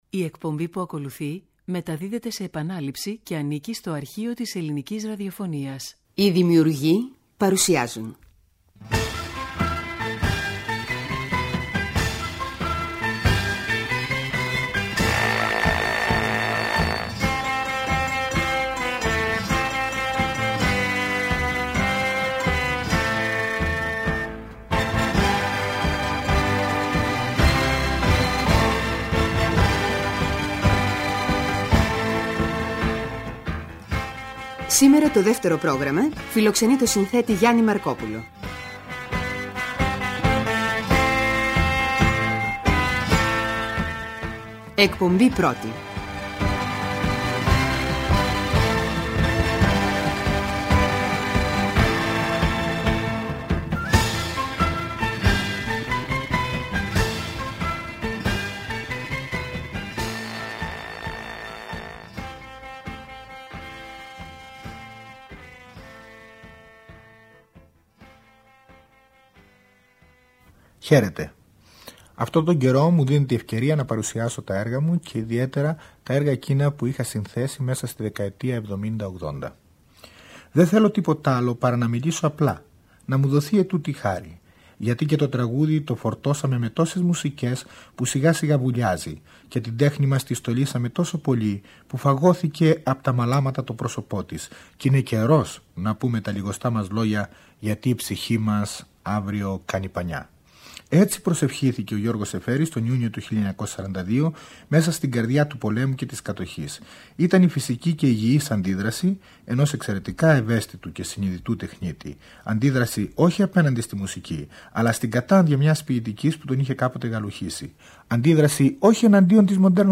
Τρίτη 13 Ιουνίου, στις 11:00 το πρωί, ακούστε από το Αρχείο της Ελληνικής Ραδιοφωνίας τα 4 επεισόδια από τους “Έλληνες Δημιουργούς”, όπου ο Γιάννης Μαρκόπουλος αυτοβιογραφείται και παρουσιάζει ένα μεγάλο μέρος του έργου, με τον δικό του τρόπο.